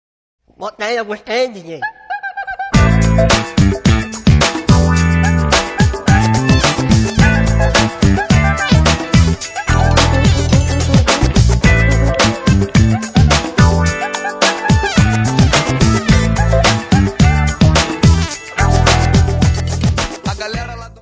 Music Category/Genre:  Pop / Rock